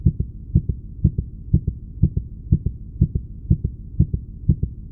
Heartbeat.ogg